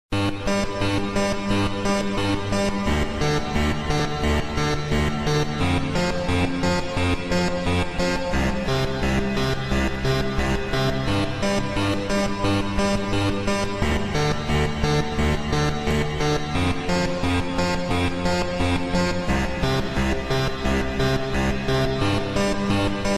Instrument 2
Also mal abgsehen davon, dass das Melodieinstrument in beiden fällen extrem unsauber klingt (vielleicht ist das auch gewollt?), würde ich auf Synthesizer/Keyboard tippen.
Naja, stimmt, hatte mir echt nur das erste angehört, aber das 2. Beispiel klingt doch garnicht mehr nach Theremin...